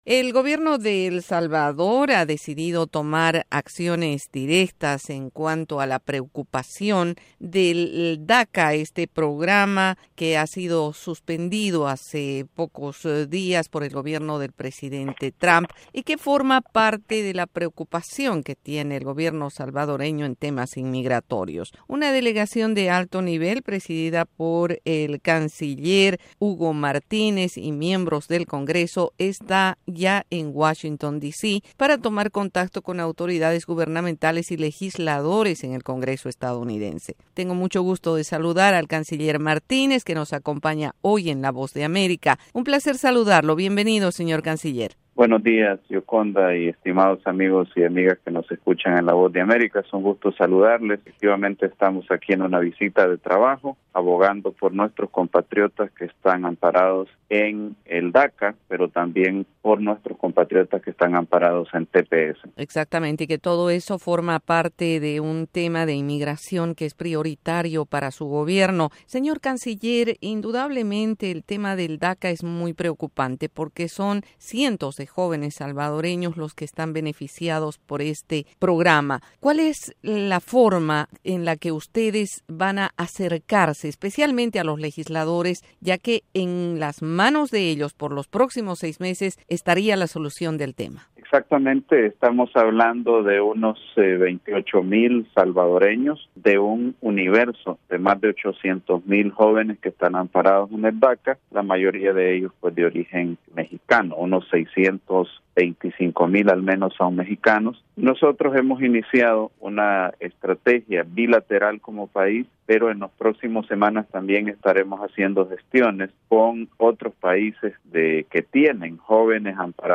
Hugo Martínez, canciller de El Salvador dialoga sobre el DACA y el TPS